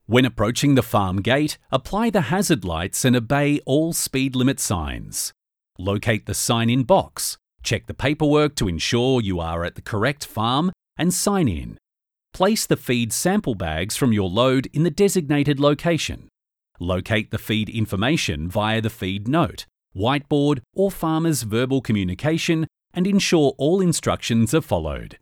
Male
English (Australian)
Warm and very flexible. Hard sell to natural with a great ability to bring the script to life.
Explainer Videos
Words that describe my voice are Warm, Natural, Friendly.
1013instructional.mp3